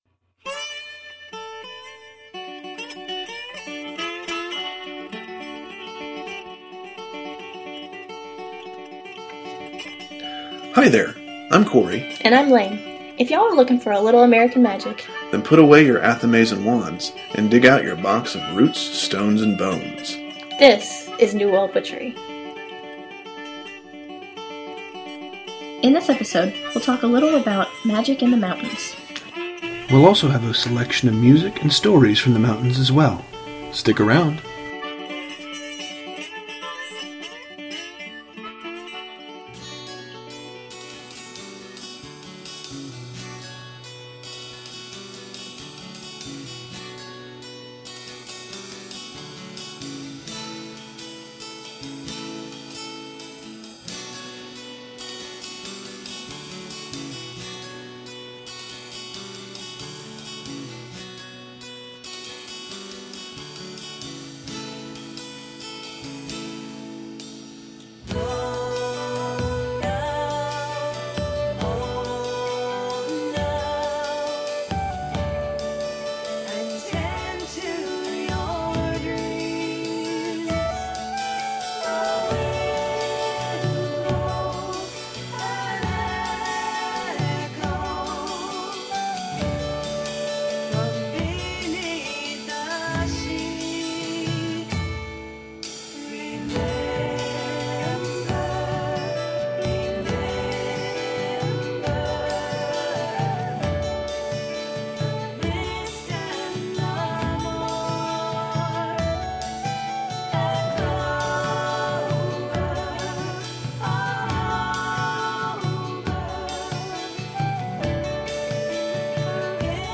Today we look at magical lore from the mountains (Ozarks and Appalachians). We’ll have discussion, stories, and even some music!